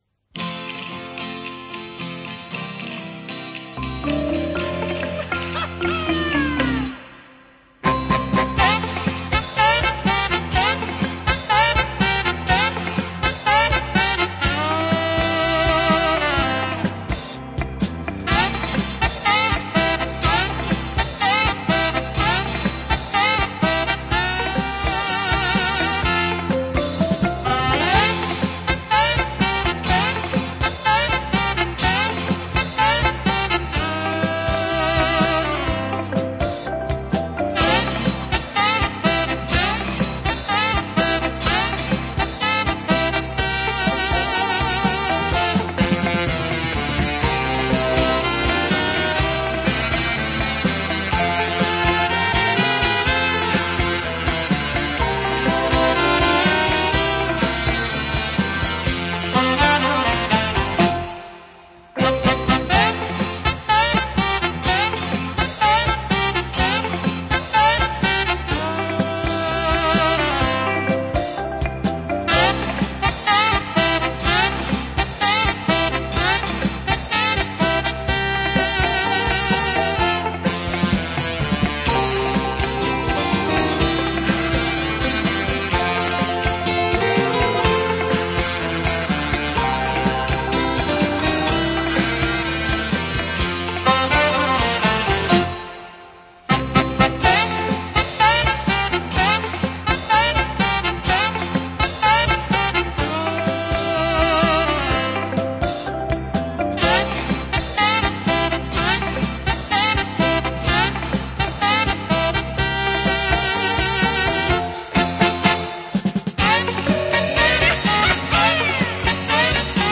Жанр: Easy Listening, Sax
Носитель: LP